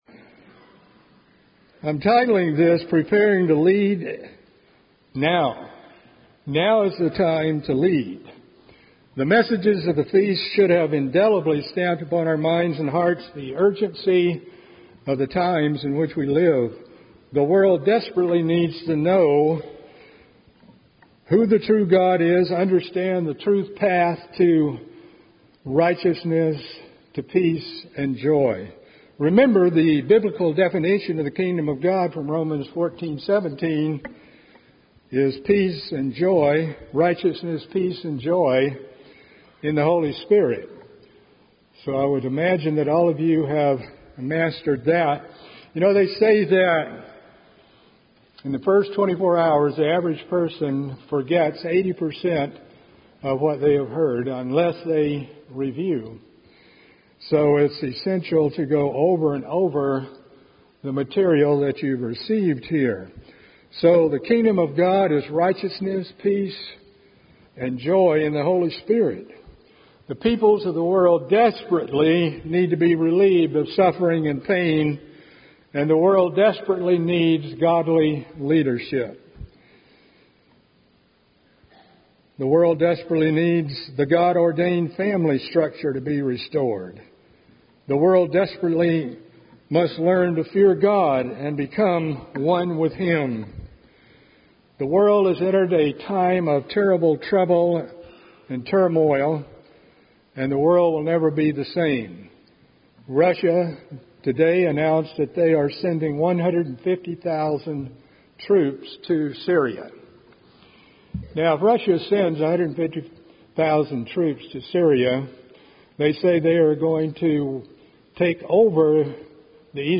This sermon was given at the Galveston, Texas 2015 Feast site.